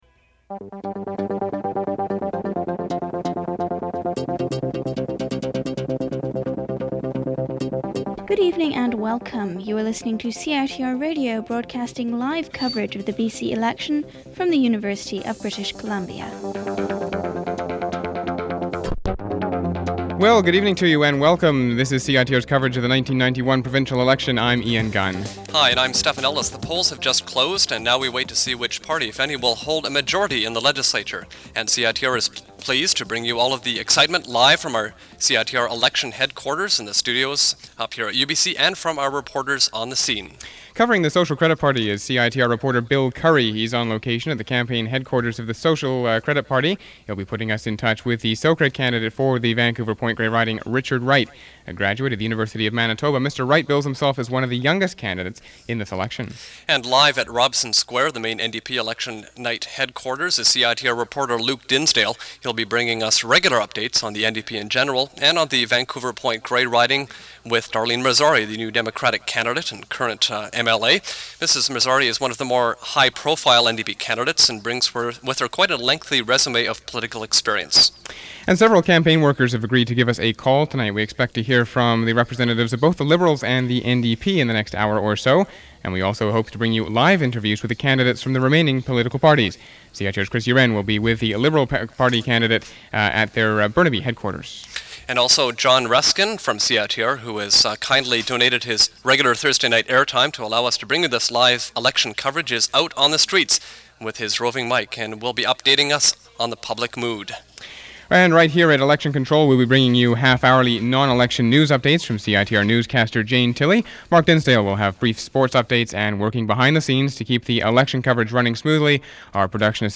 Recording of a live CiTR News, Sports and Current Affairs broadcast covering the 1991 British Columbia general election, featuring report, debate and interviews conducted by a large number of CiTR programmers.